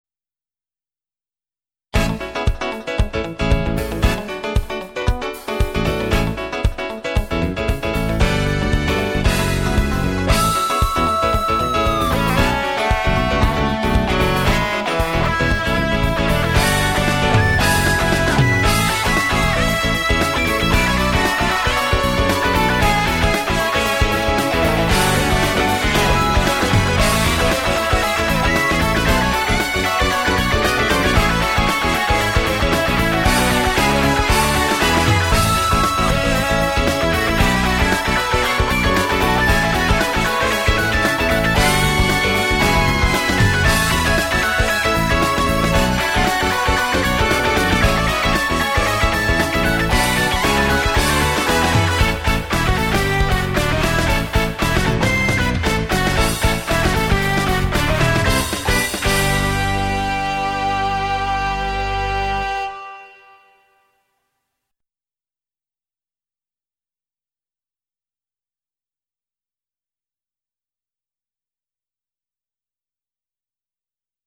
Fusion-Resort-1(1161K)